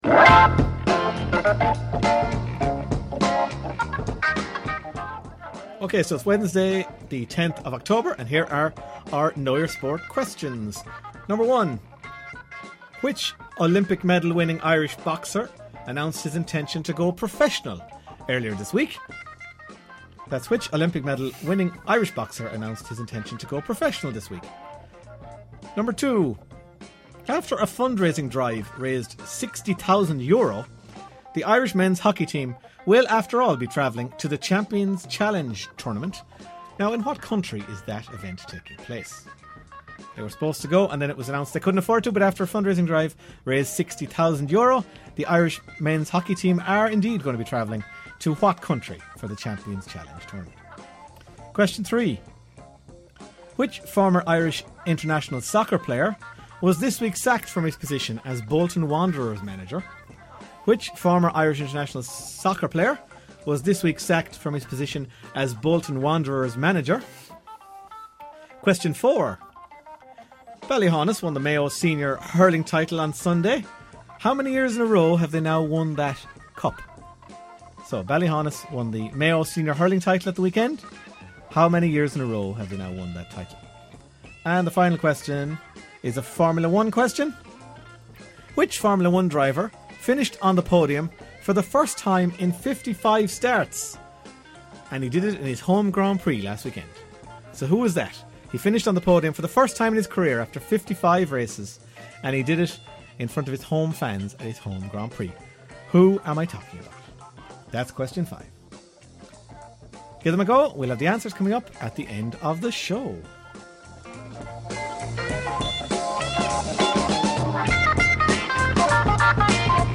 Five sports trivia questions from the 'Half-time Team Talk' show on Claremorris Community Radio.